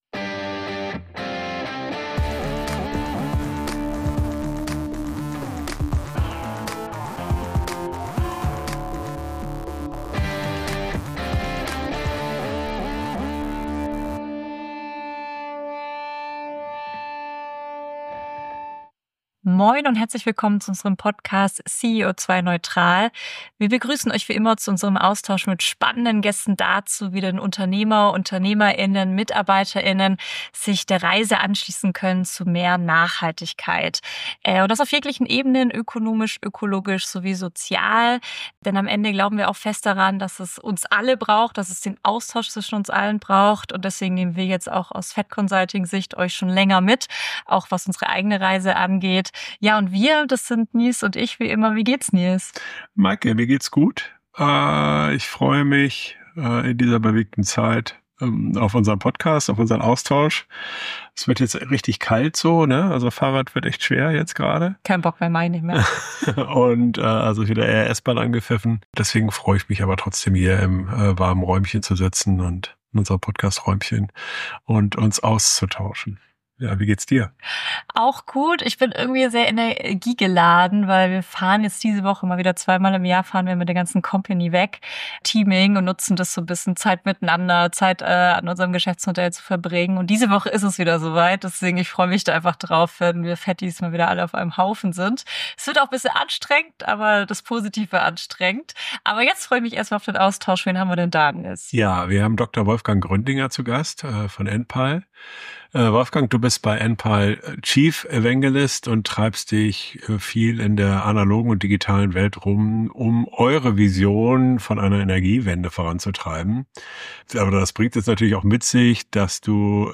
Auf dem Weg zur Energiewende - ein Gespräch mit Enpal ~ CEO2-neutral - Der Interview-Podcast für mehr Nachhaltigkeit im Unternehmen Podcast